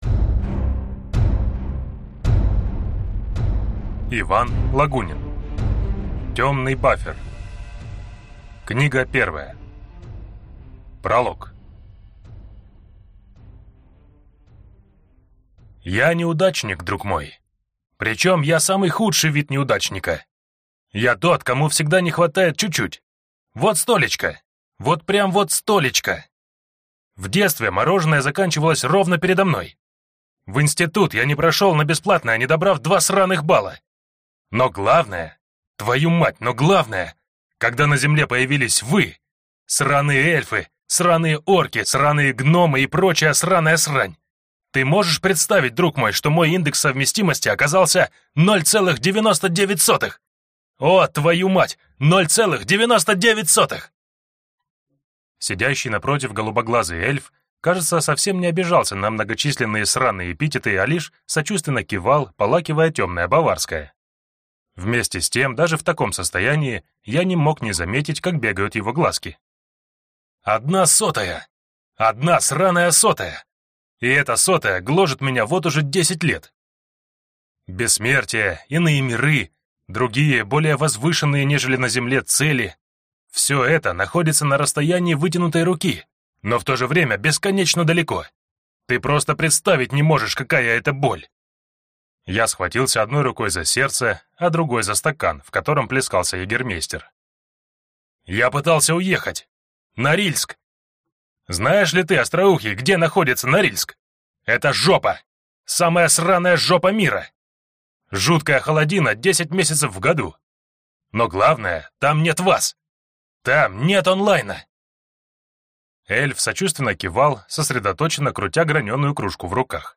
Аудиокнига Темный баффер. Книга 1 | Библиотека аудиокниг